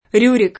vai all'elenco alfabetico delle voci ingrandisci il carattere 100% rimpicciolisci il carattere stampa invia tramite posta elettronica codividi su Facebook Rjurik [russo r L2 rik ] pers. m. stor. — adatt. in Occidente come Rurik [ r 2 rik ]